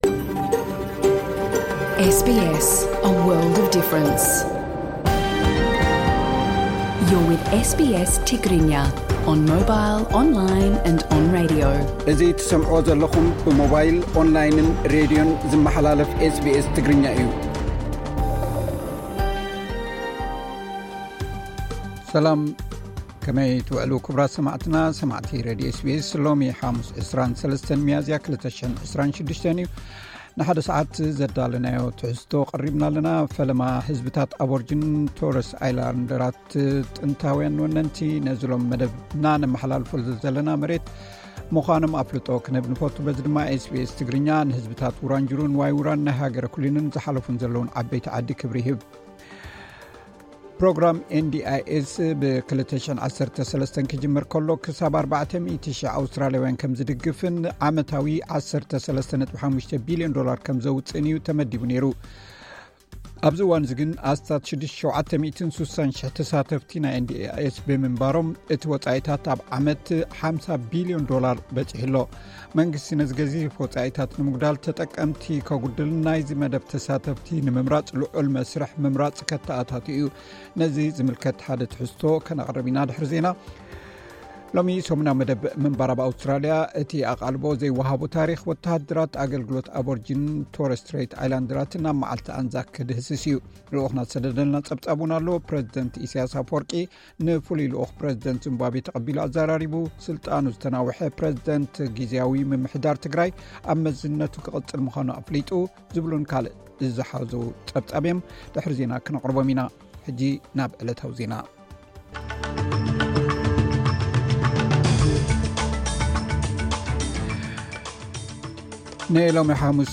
ን ሎሚ ሓሙስ 23 ሚያዝያ 2026 ዘዳለናዮ ንሓደ ሰዓት ዝጸንሕ መደብና፥ ዜና፡ ትንታነ ዜና፡ ጸብጻብ ልኡኽና፡ ቃለ መሕትትን ምንባር ኣብ ኣውስትራሊያ ዝሓዘ እዩ።